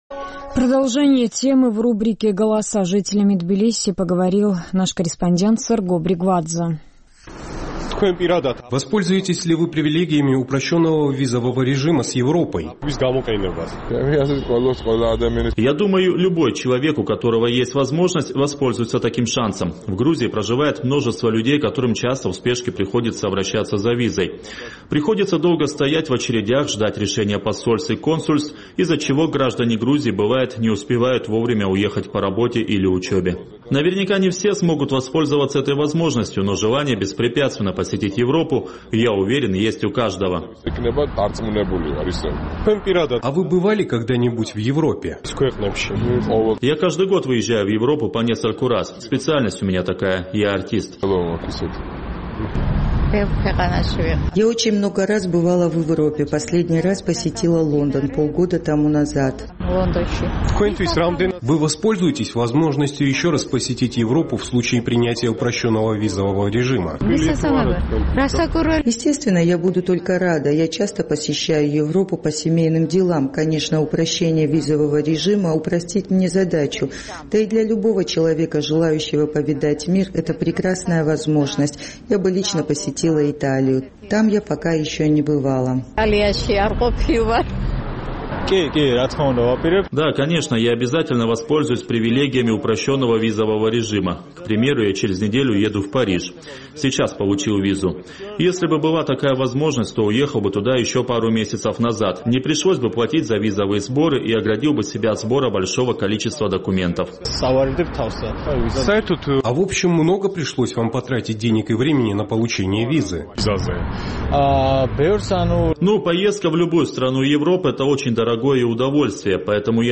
Наш тбилисский корреспондент поинтересовался у жителей столицы, планируют ли они воспользоваться возможным упрощением визового режима.